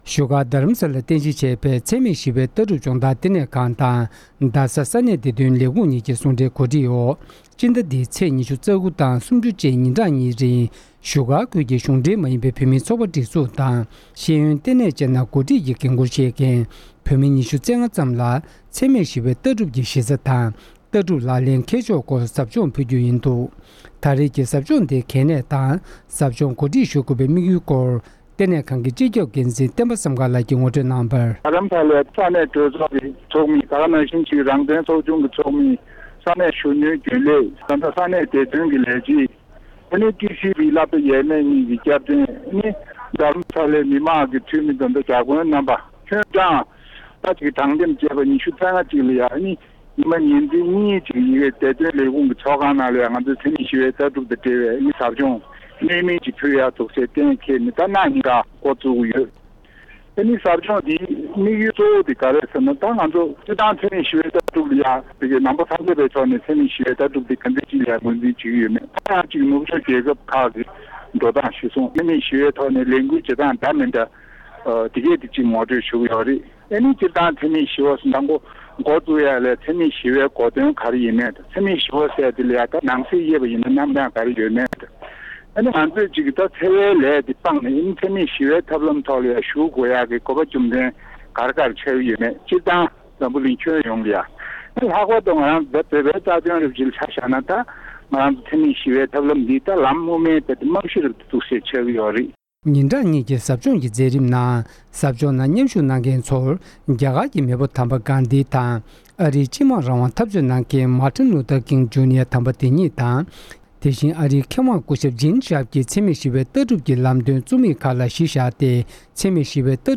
གསར་འགྱུར་དང་འབྲེལ་བའི་ལས་རིམ་ནང་།